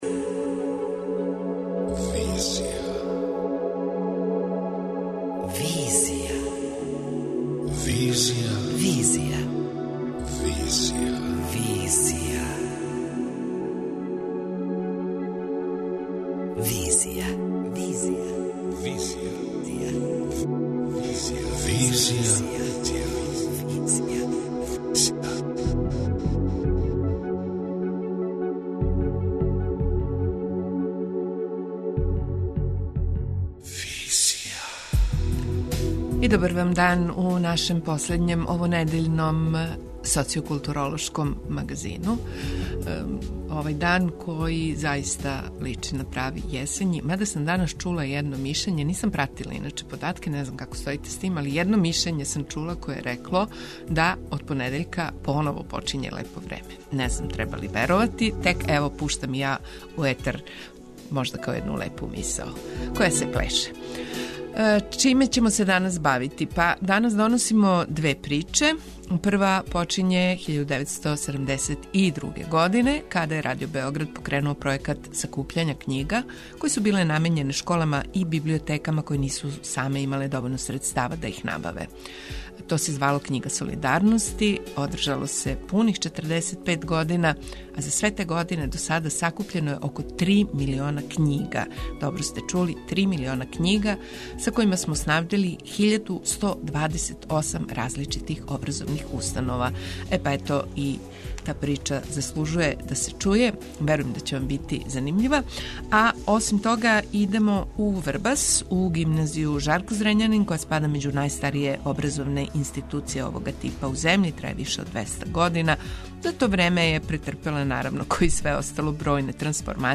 преузми : 27.23 MB Визија Autor: Београд 202 Социо-културолошки магазин, који прати савремене друштвене феномене.